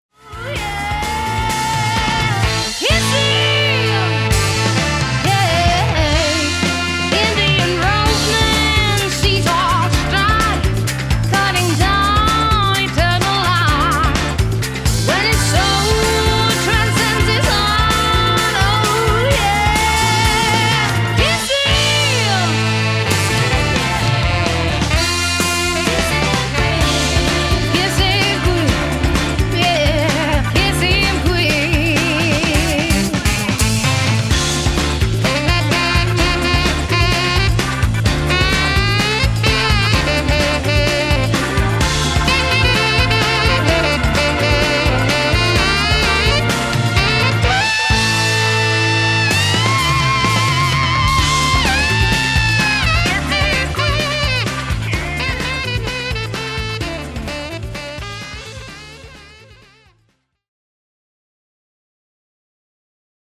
CLASSIC SONG COVERS
Backing Vocals…
Alto and Tenor Sax
Killer Sax Solo
Drums
All Keyboards and Guitars
Fender ’63 Precision Bass